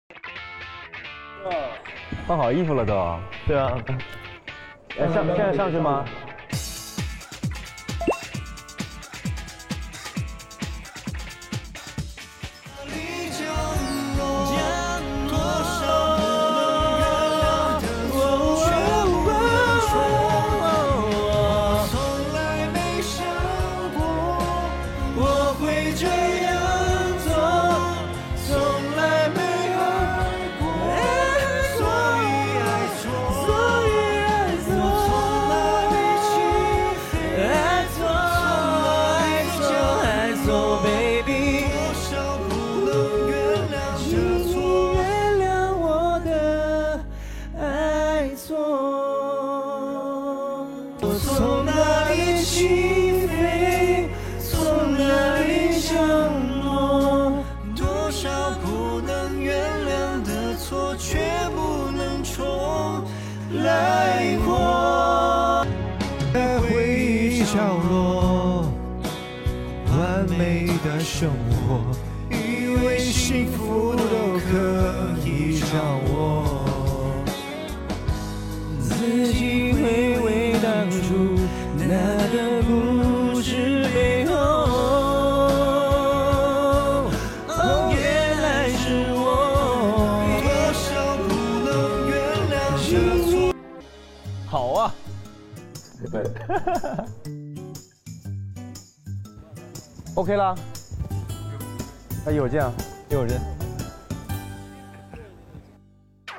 29.08.2025 ทีเซอร์ Hi6 เบื้องหลังซ้อมร้องเพลง